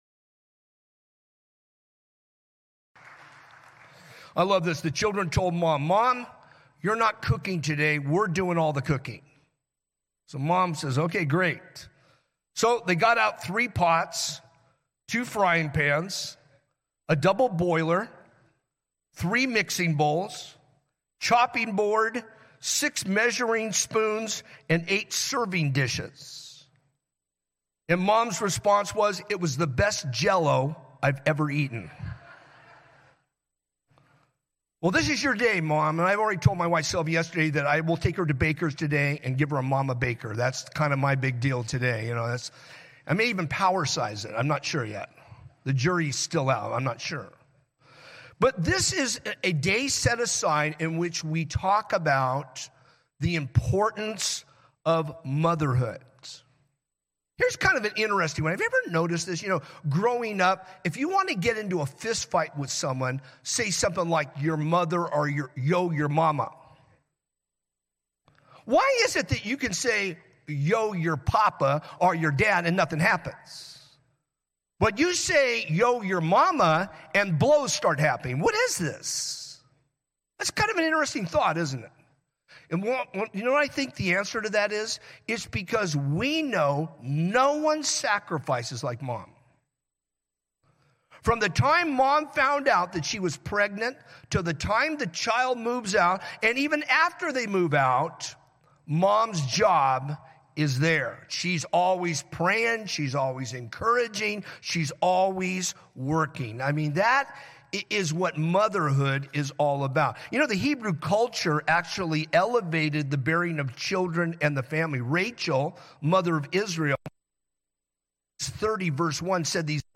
A message from the series "Mother's Day."
From Series: "Sunday Morning - 10:30"